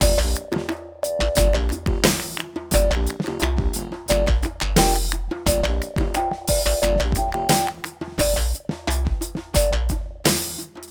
Plus a couple drum/etc loops (44K/16bit, happy octatrack to those that celebrate)